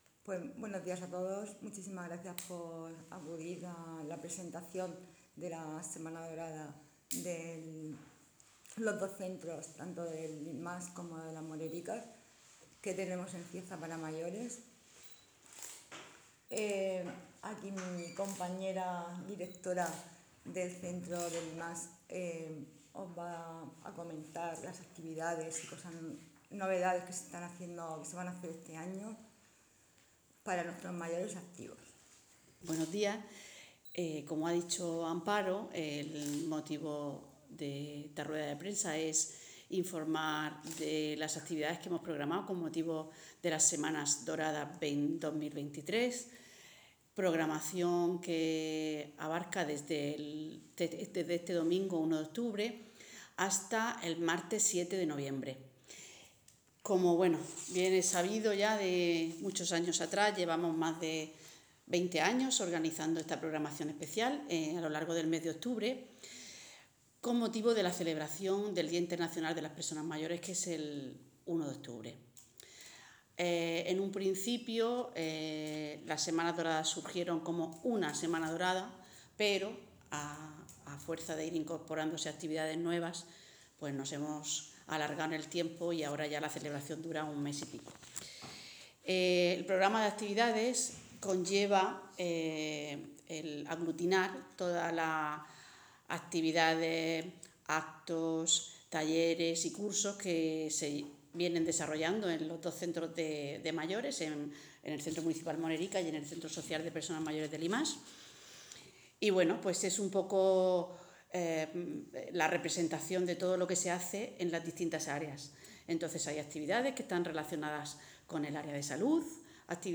Audio statements from the Councillor for Senior Citizens of the Cieza City Council